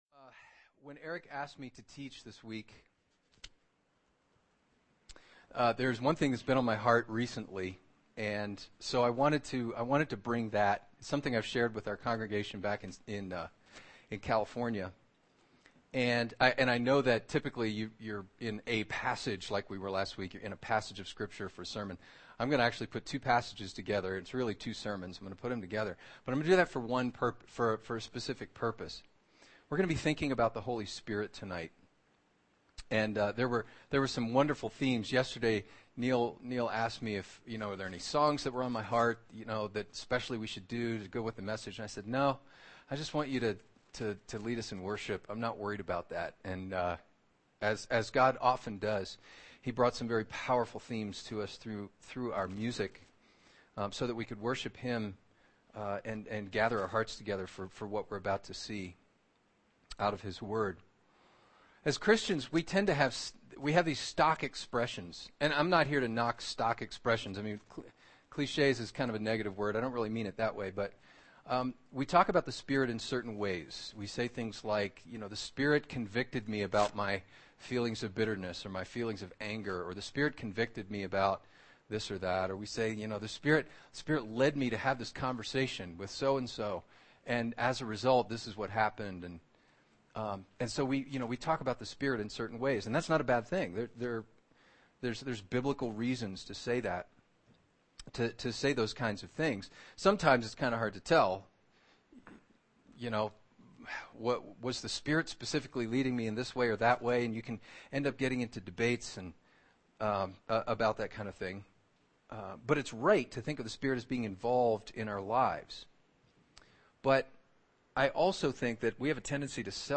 [sermon]